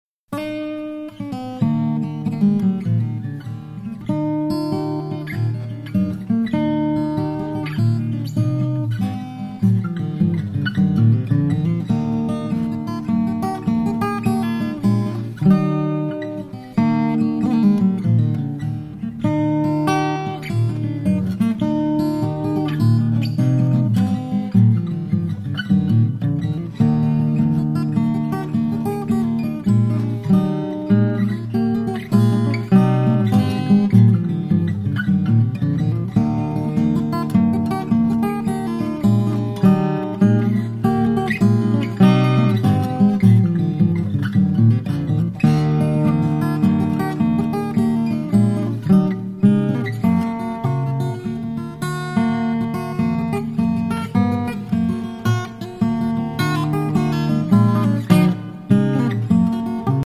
- a collection of Irish tunes arraneged for solo guitar -
It is full of pure and lyrical acoustic guitar solos!
In this impressive acoustic guitar instrumental album